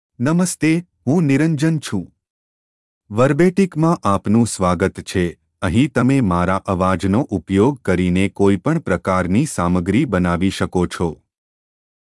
MaleGujarati (India)
Niranjan is a male AI voice for Gujarati (India).
Voice sample
Male
Gujarati (India)
Niranjan delivers clear pronunciation with authentic India Gujarati intonation, making your content sound professionally produced.